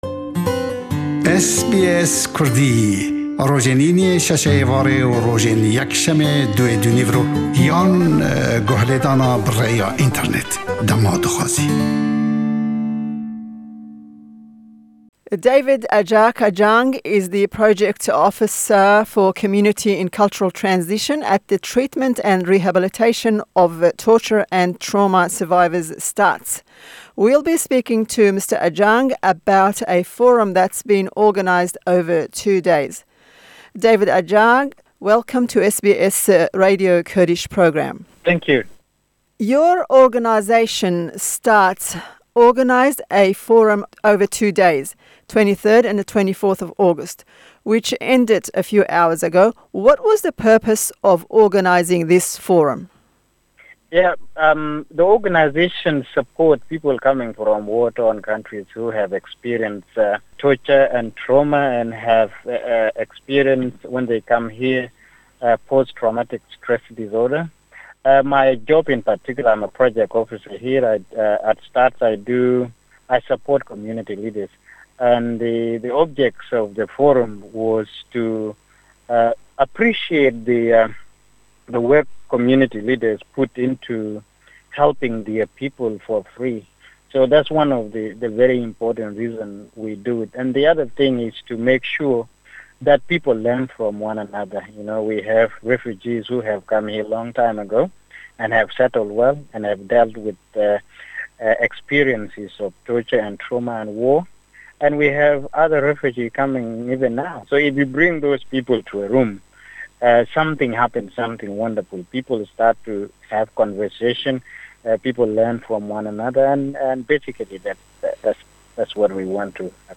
Hevpeyvîn bi zimanî Îngilîziye.